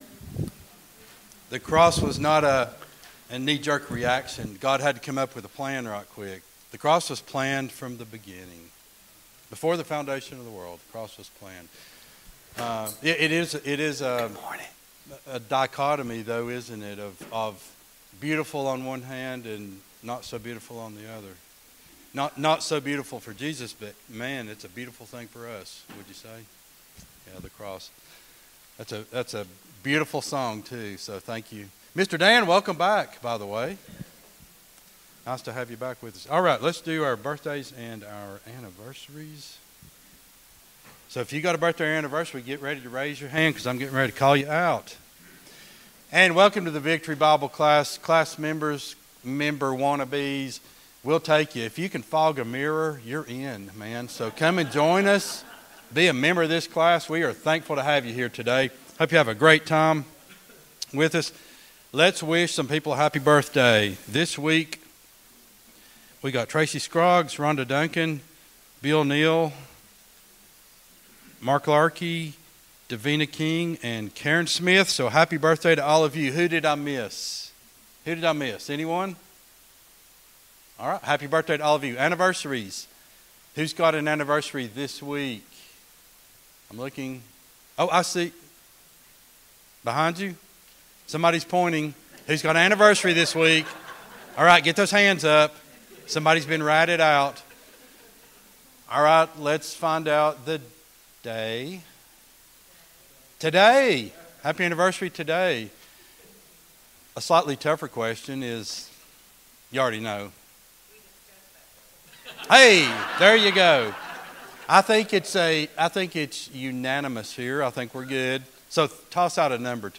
11-26-23 Sunday School Lesson | Buffalo Ridge Baptist Church